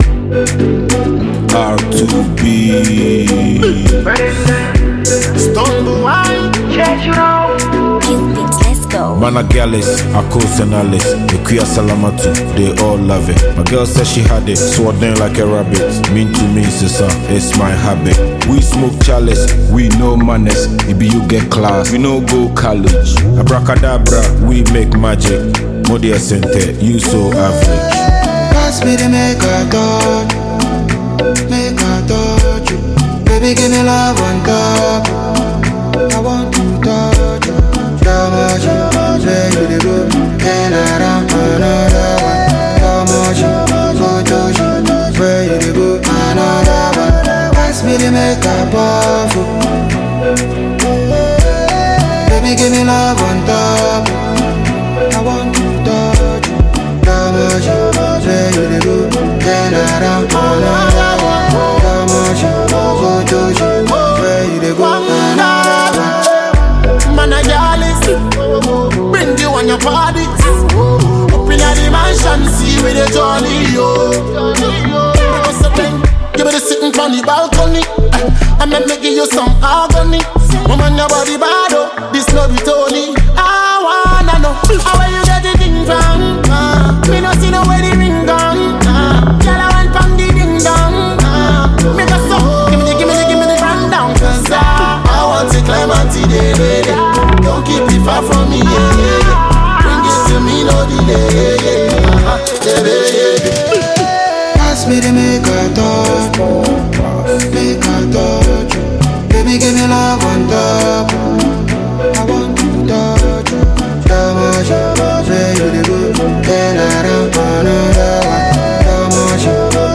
One of the biggest Afrobeats duo in Africa